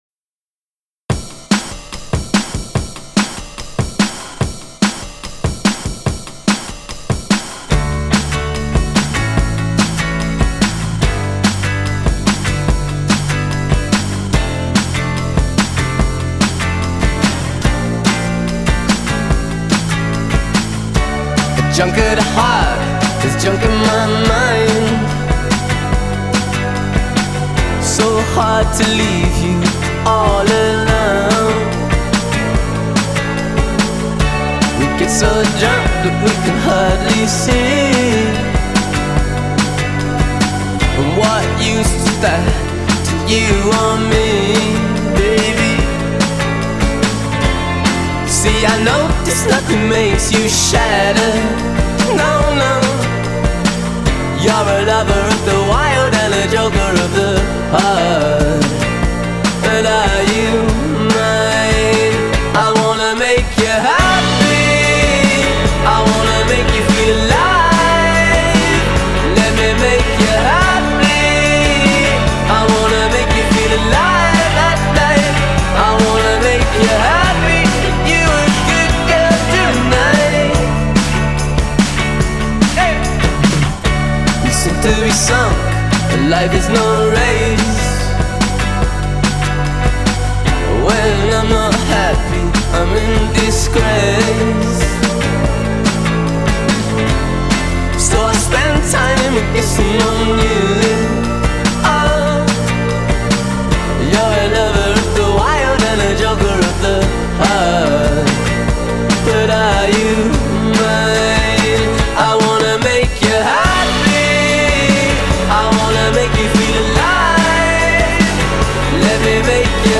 Style: Indie rock